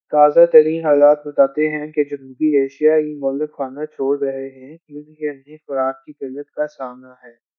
Spoofed_Tacotron/Speaker_13/270.wav · CSALT/deepfake_detection_dataset_urdu at main